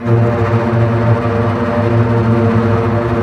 Index of /90_sSampleCDs/Roland LCDP08 Symphony Orchestra/STR_Cbs Bow FX/STR_Cbs Tremolo